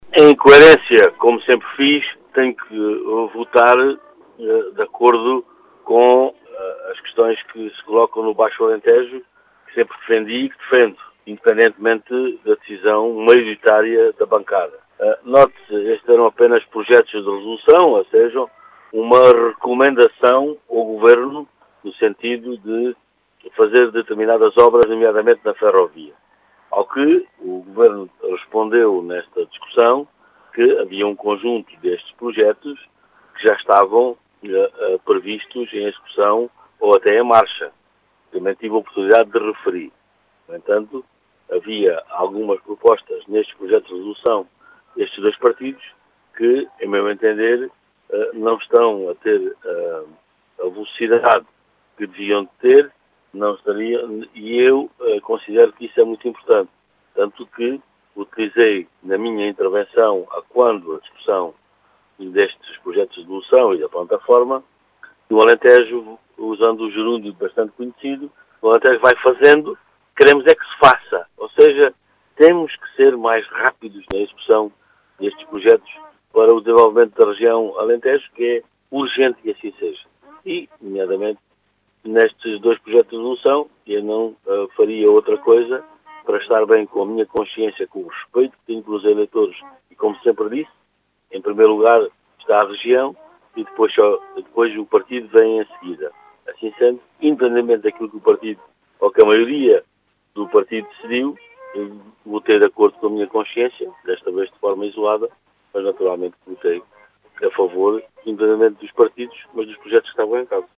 Em declarações à Rádio Vidigueira, o parlamentar socialista justificou o sentido de voto em “coerência”, com a defesa do desenvolvimento da região, mas salientou que estes projetos de resolução incluem projetos que “já estão em marcha”, pese embora, segundo Pedro do Carmo, não estejam a ter “a velocidade que deviam”.